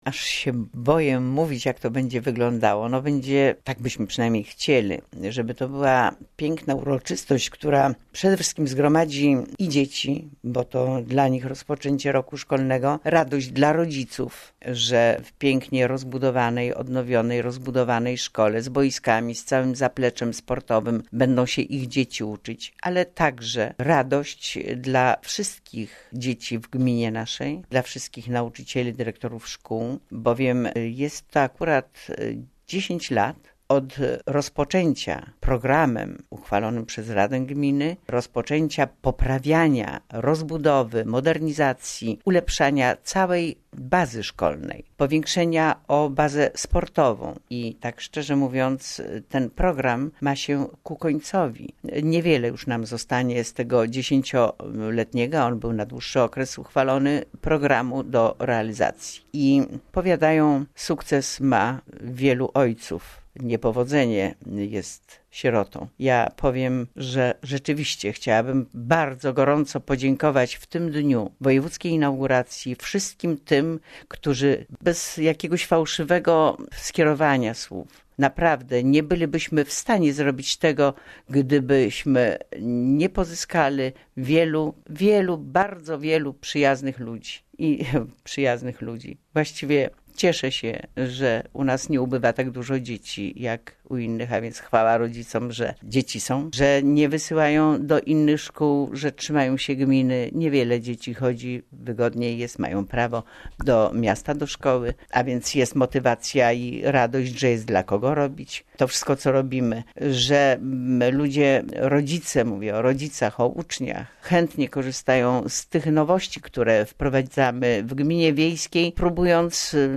Posłuchaj wypowiedzi Wójta